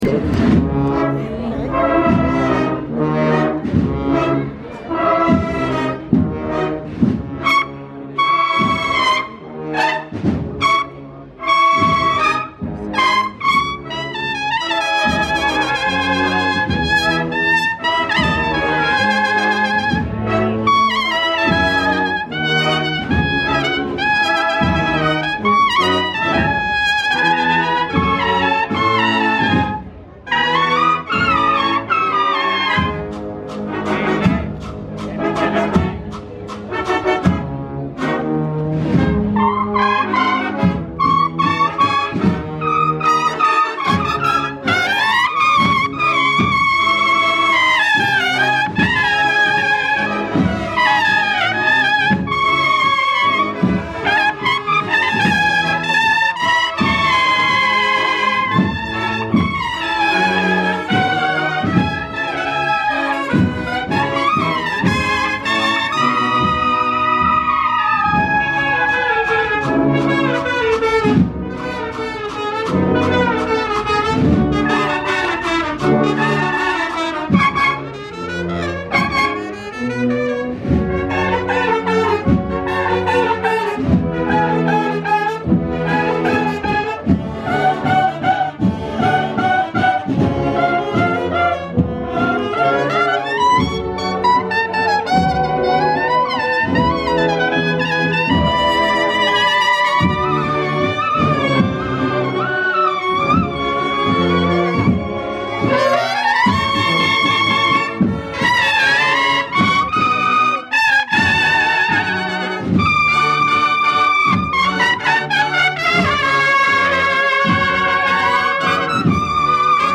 Semana Santa Soundtrack
Played in exaltation or commiseration, to a victorious tune or on a darker note, the music of the marching bands in-tow is spectacular. The horns, snare drums, big drums, clarinets; you’ll want them all closer, louder.
Returning to the march, or better put, the crawl, the men release a harmonized heave – huuvvh! – the crowd applauds, the music rises, and the pasos are back on their way.
semana-santa-music.mp3